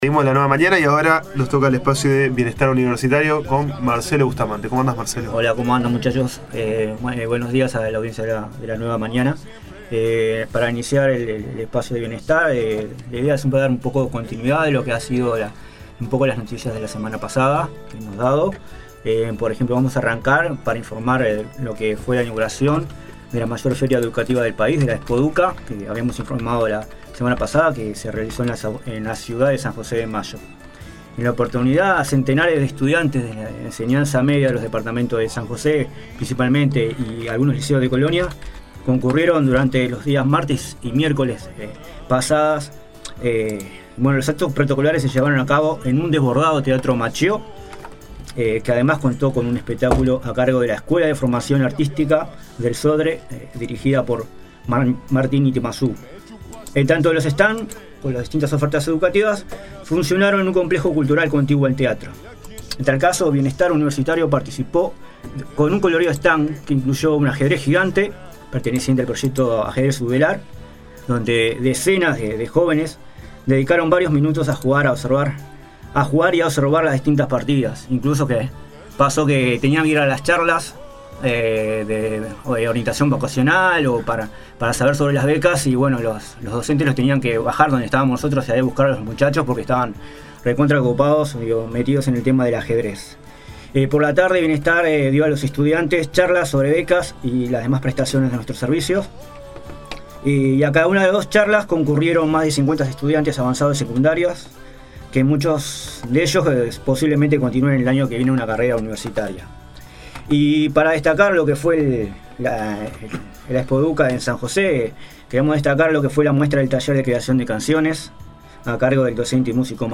concurrieron a los estudios de Uni Radio para dar a conocer el apoyo recibido y contar sus historias de vida. Además, se informó sobre la participación de Bienestar en la Expo Educa de San José y se anunció de la firma de un convenio el próximo jueves 13 con el Club Neptuno.